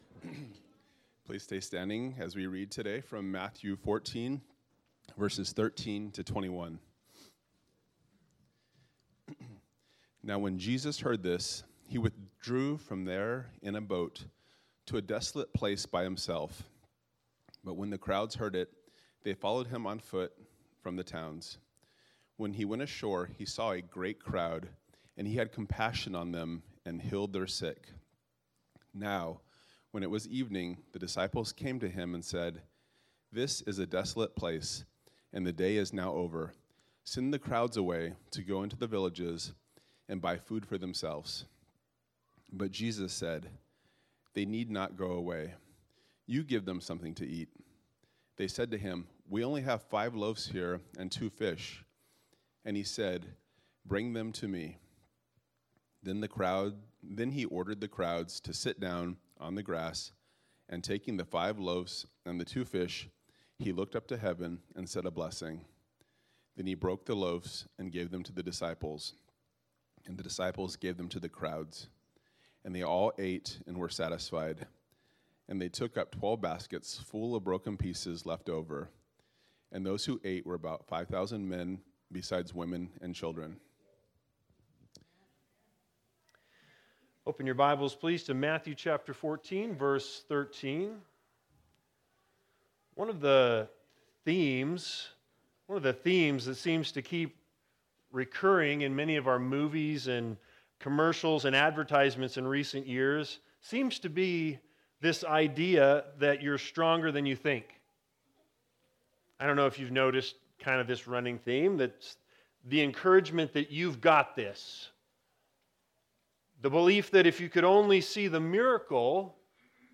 Matthew 14:13-21 Service Type: Sunday Sermons Big Idea